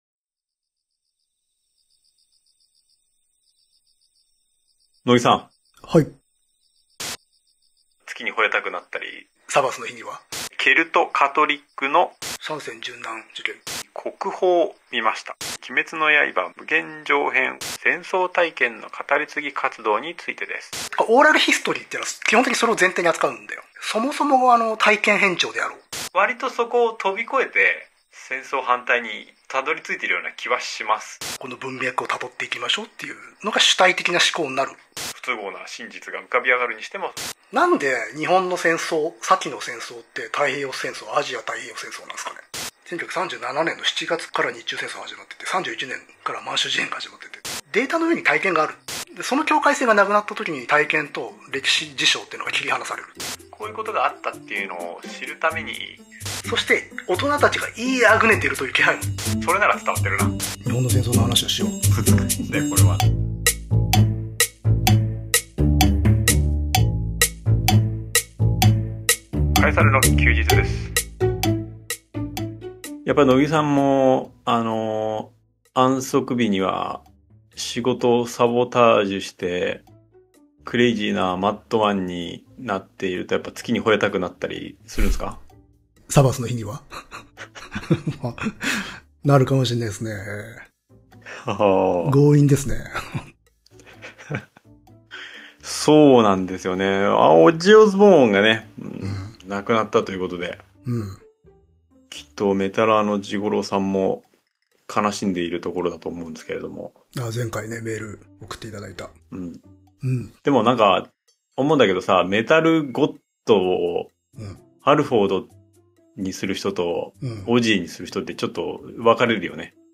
クリエーター2人が、賽の目のお題に従ってトーク。お題は主に本、歴史、アートなどのカルチャーから身近な話題まで。